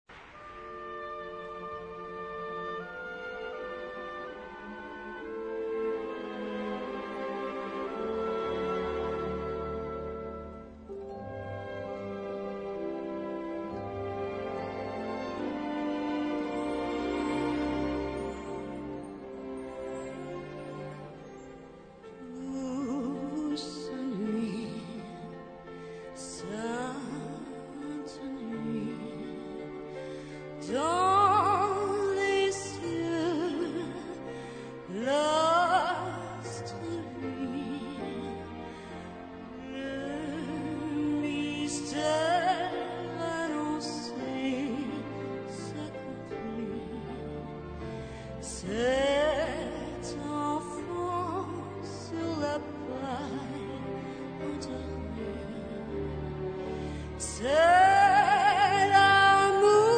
key: G-major